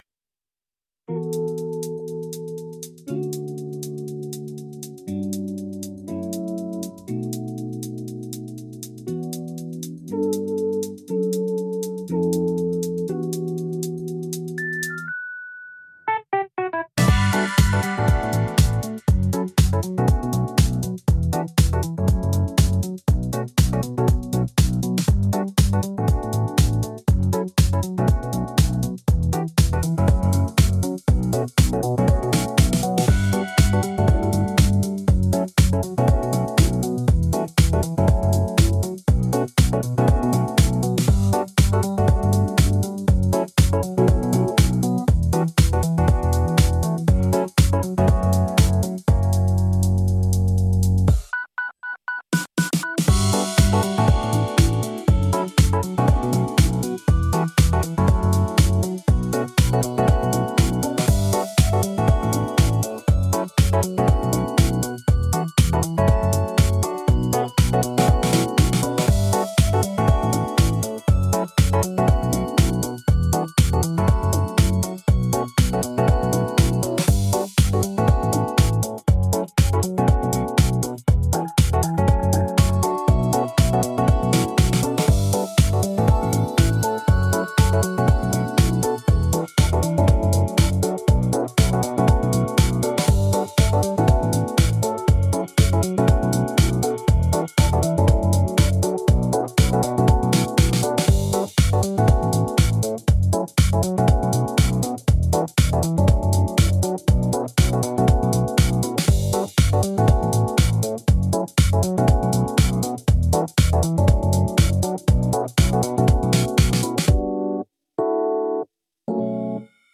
AI音楽
一応歌詞なしとAIが弾いている動画も追加しときましょう。
プルンプルンのリップ-Instrumental.mp3